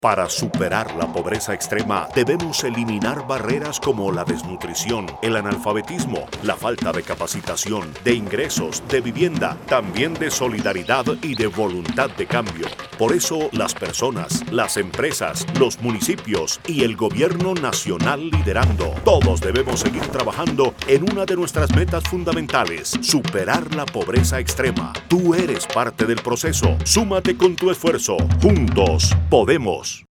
Campaña promocional - Red Juntos